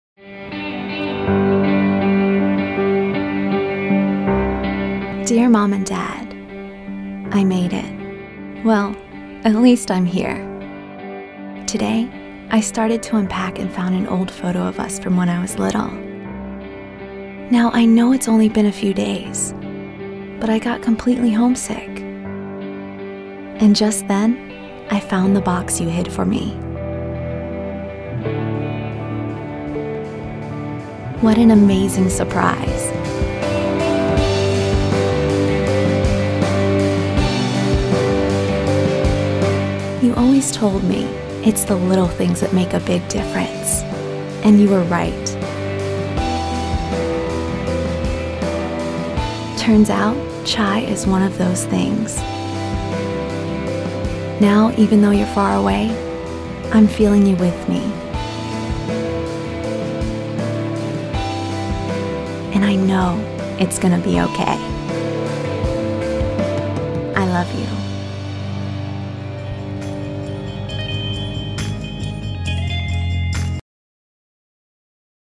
Warm, emotional, personal
Anthem